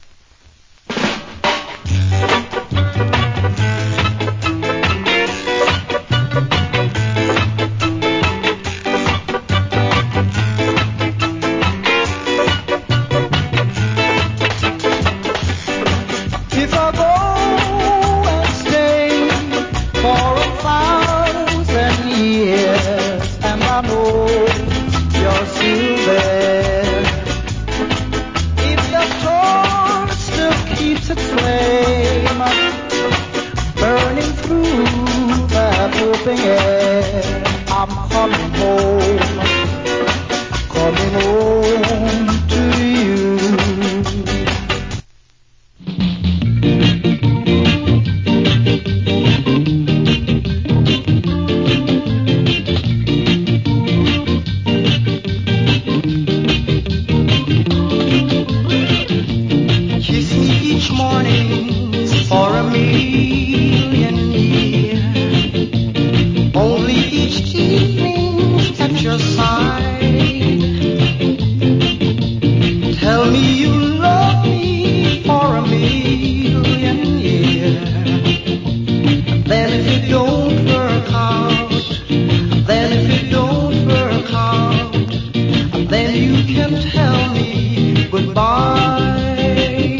Old Hits Early Reggae Inst.